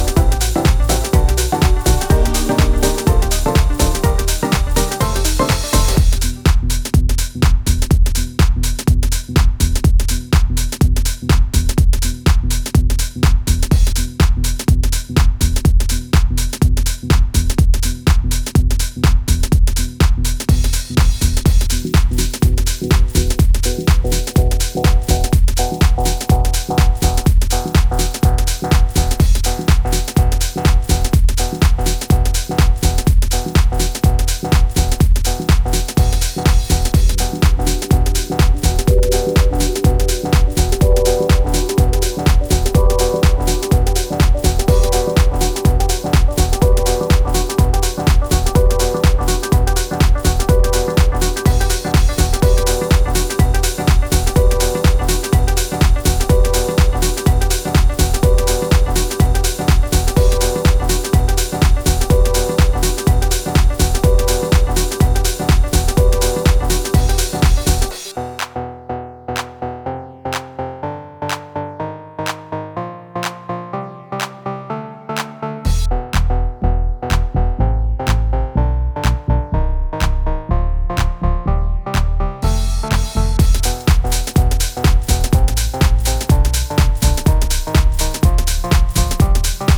しっかりとしたボトムと浮遊するコードを軸としながらオーソドックスなインスト・ハウスを展開しています。